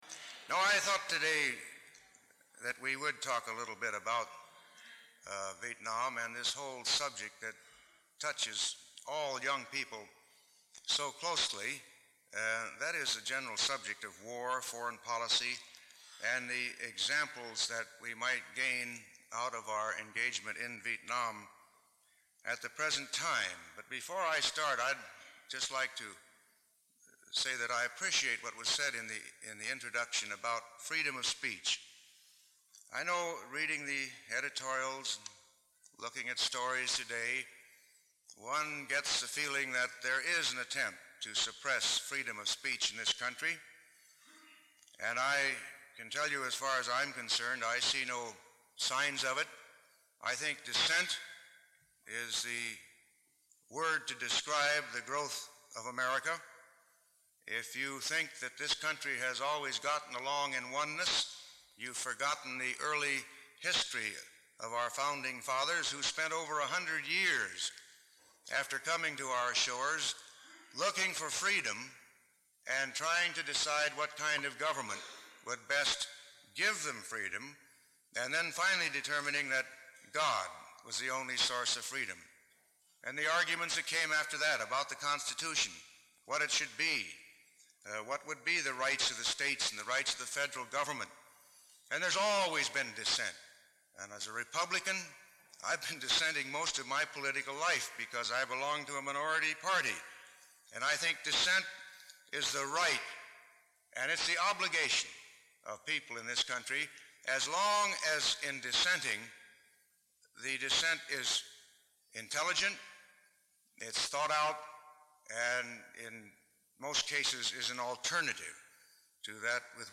Lecture Title